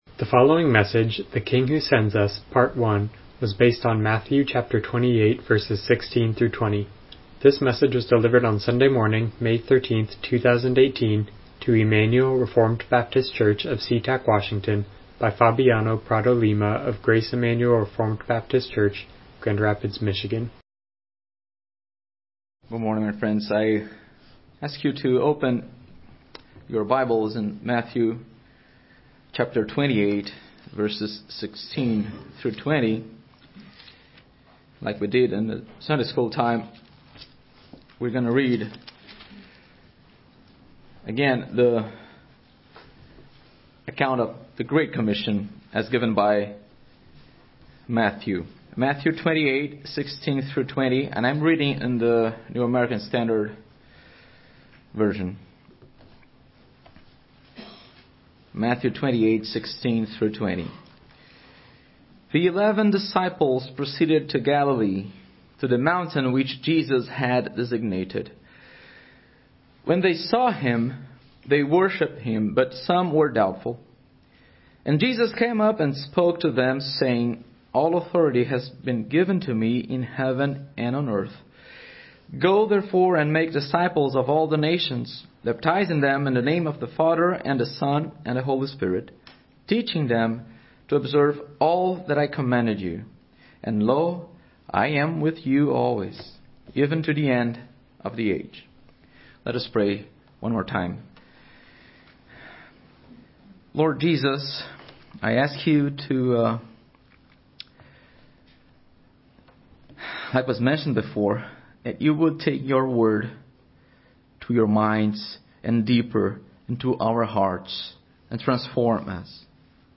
Miscellaneous Passage: Matthew 28:16-20 Service Type: Morning Worship « The Mission of the Church The King Who Sends Us